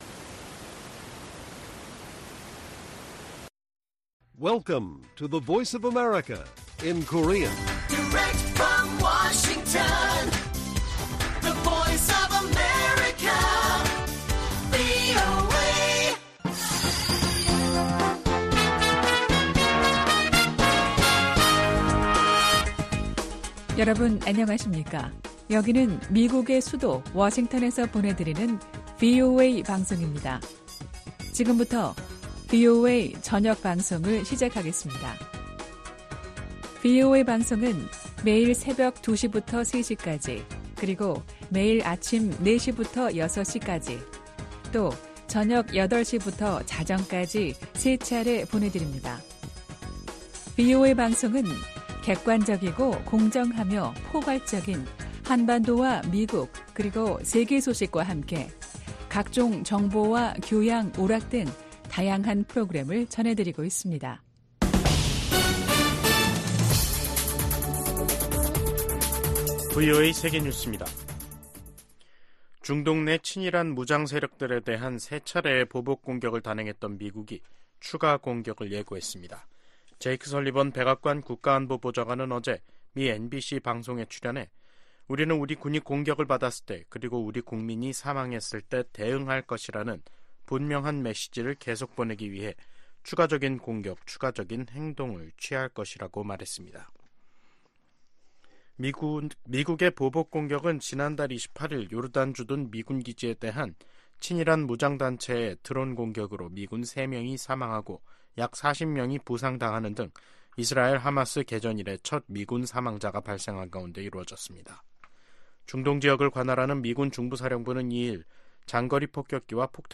VOA 한국어 간판 뉴스 프로그램 '뉴스 투데이', 2024년 2월 5일 1부 방송입니다. 북한은 순항미사일 초대형 전투부 위력 시험과 신형 지대공 미사일 시험발사를 지난 2일 진행했다고 대외 관영 ‘조선중앙통신’이 다음날 보도했습니다. 미국 정부는 잇따라 순항미사일을 발사하고 있는 북한에 도발을 자제하고 외교로 복귀하라고 촉구했습니다. 미국 정부가 미국내 한인 이산가족과 북한 가족들의 정보를 담은 기록부를 구축하도록 하는 법안이 하원에서 발의됐습니다.